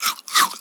comedy_bite_creature_eating_01.wav